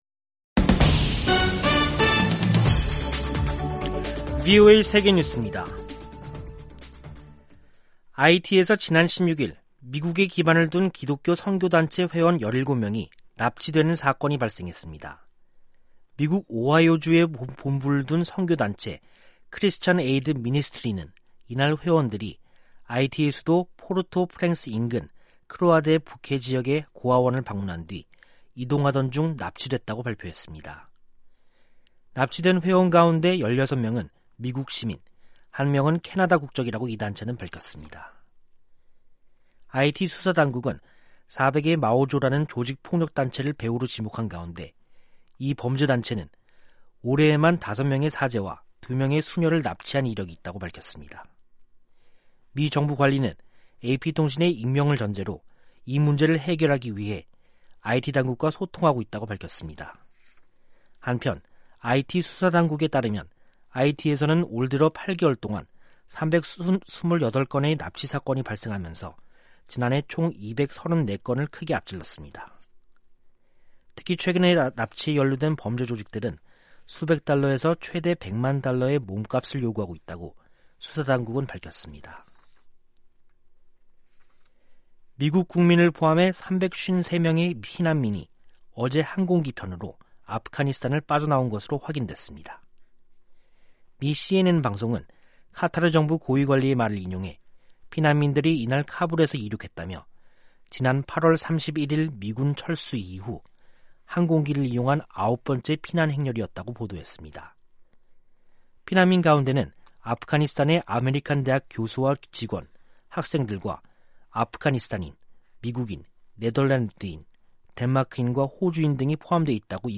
세계 뉴스와 함께 미국의 모든 것을 소개하는 '생방송 여기는 워싱턴입니다', 2021년 10월 18일 저녁 방송입니다. '지구촌 오늘'에서는 중국 3분기 경제성장률이 시장 전망보다 낮은 4.9%를 기록한 소식, '아메리카 나우'에서는 앤서니 파우치 국립 알레르기·전염병 연구소장이 코로나 백신 의무화를 적극 옹호한 이야기 전해드립니다.